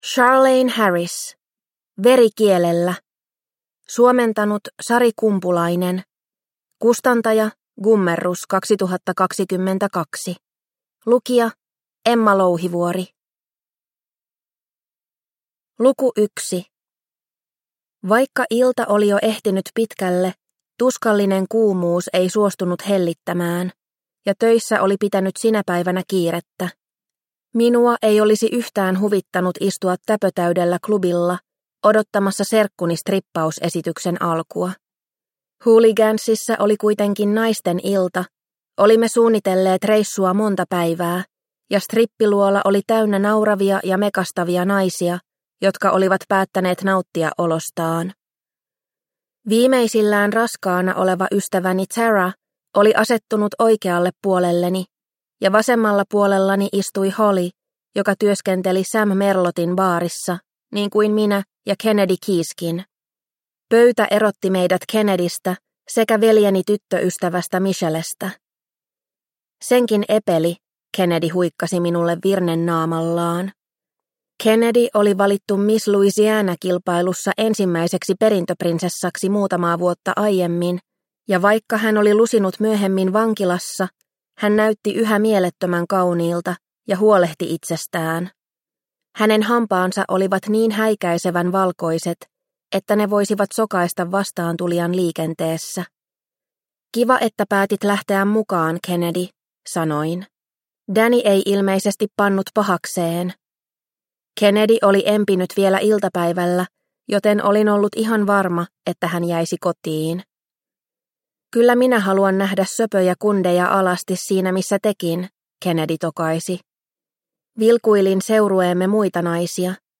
Veri kielellä – Ljudbok – Laddas ner